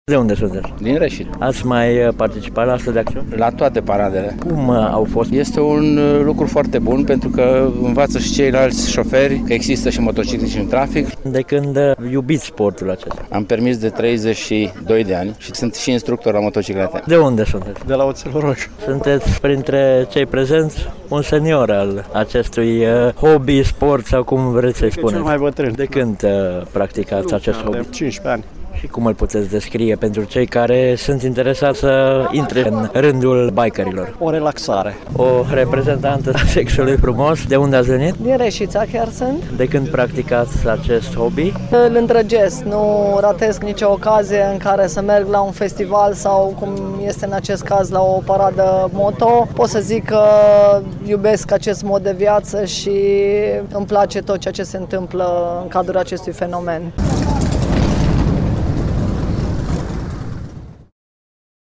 De la bikerii prezenţi am aflat cum găsesc aceştia organizarea unor astfel de acţiuni: